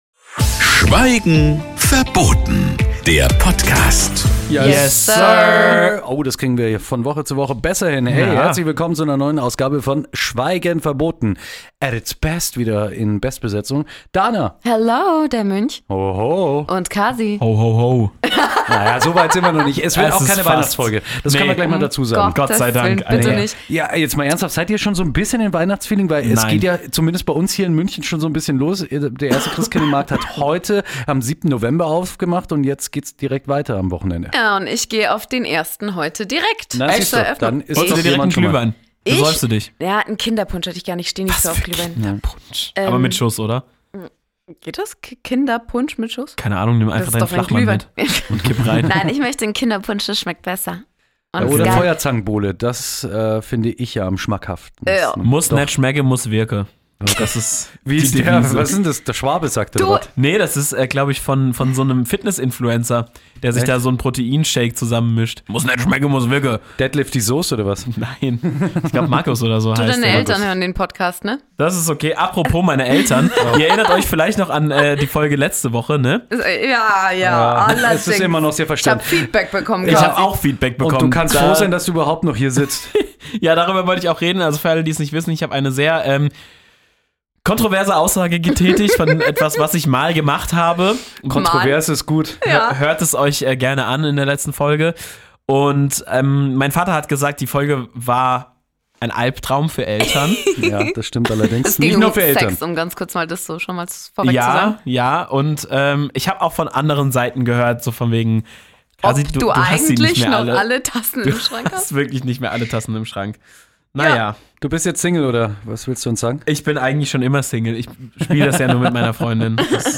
Wir sind drei Leute, die über Themen reden wie sauschlechte Anmachsprüche, die Häuser unserer Träume und manchmal auch sinnvolle Sachen.